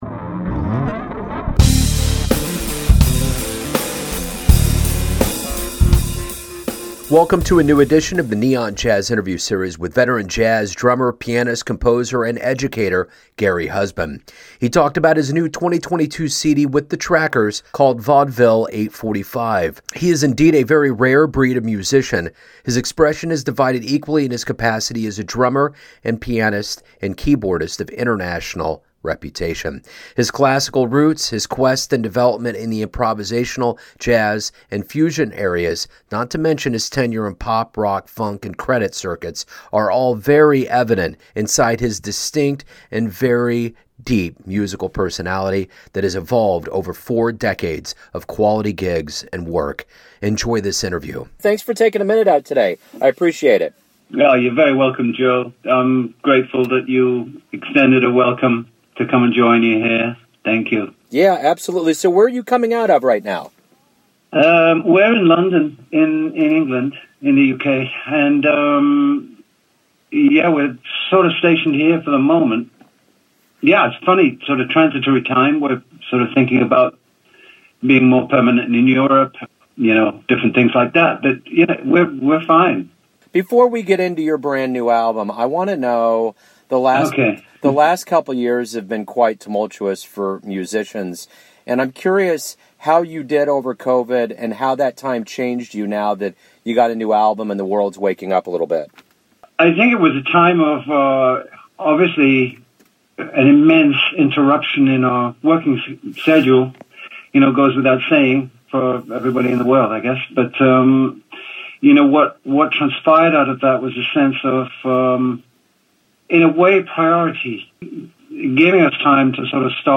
The Gary Husband Podcast Interview.mp3